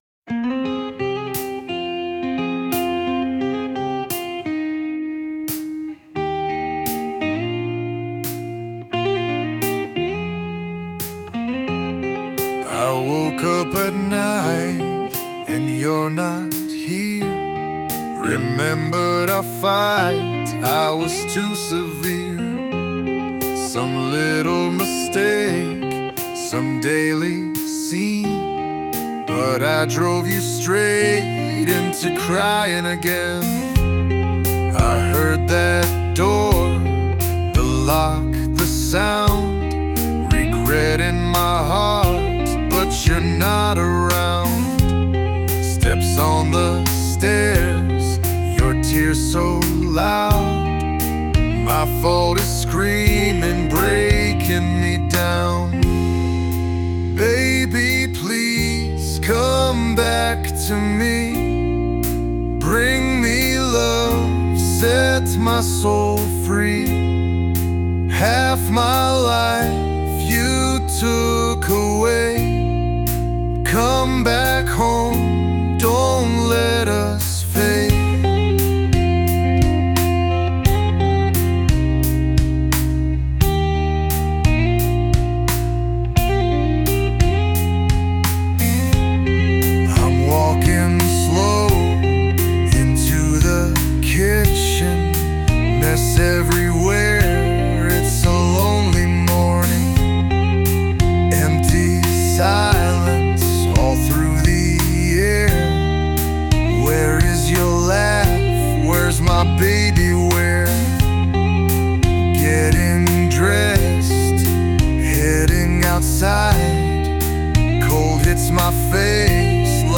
English, Soul, Blues, Rock, Country | 13.04.2025 19:15